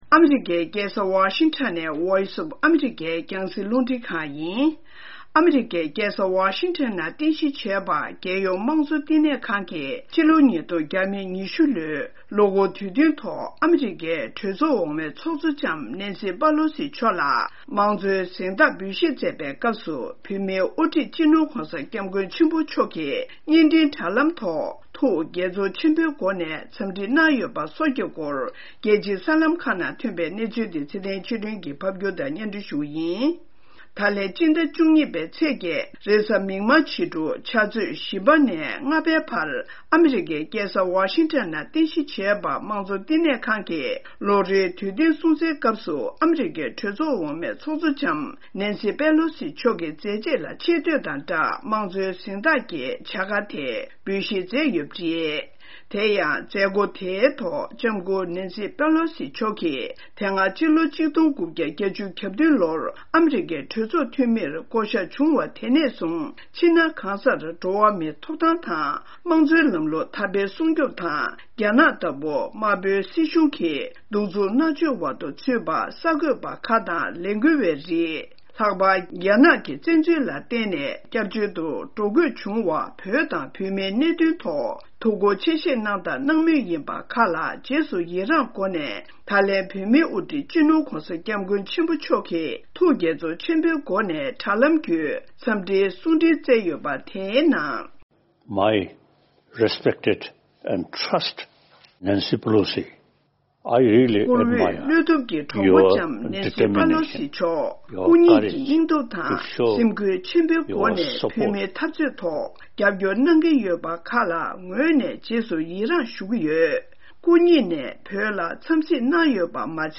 ༄༅།། ཨ་རིའི་རྒྱལ་ས་ཝ་ཤིན་ཊོན་གྱི་རྒྱལ་ཡོངས་དམངས་གཙོ་བསྟི་གནས་ཁང་གིས་ཕྱིས་ཚེས་༨་ཉིན་ཨ་མི་རི་ཀའི་གྲོས་ཚོགས་འོག་མའི་ཚོགས་གཙོ་ནན་སི་ཕེ་ལོ་སི་ལ་དམངས་གཙོའི་གཟེངས་རྟགས་འབུལ་བཞེས་ཀྱི་མཛད་སྒོ་ཚོགས་པ་དེའི་སྐབས་སུ་བོད་ཀྱི་བླ་ན་མེད་པའི་དབུ་ཁྲིད་སྤྱི་ནོར་༧གོང་ས་ཏཱ་ལའི་བླ་མ་མཆོག་གིས་བརྙན་འཕྲིན་དྲྭ་ལམ་བརྒྱུད་